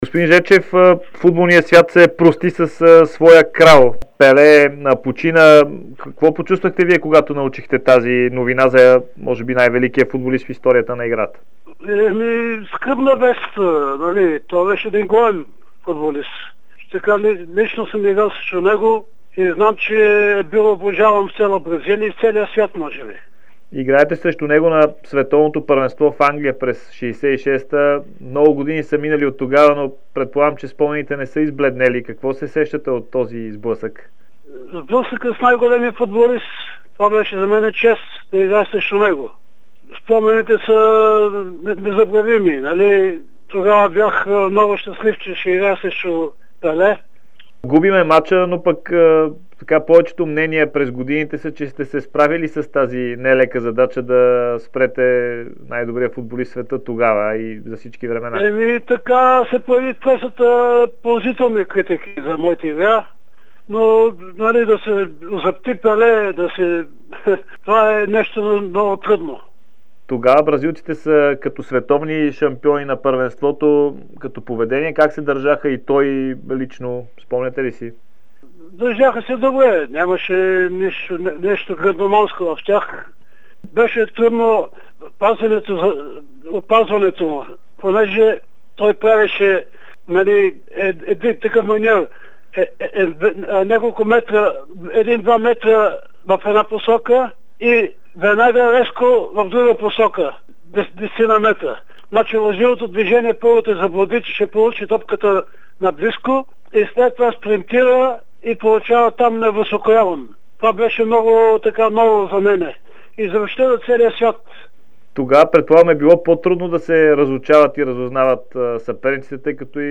Добромир Жечев пред Дарик и dsport за срещата си с Пеле на Мондиал'66